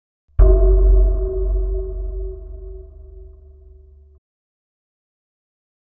AV_Deepimpact_FX_01
AV_Deepimpact_FX_01.wav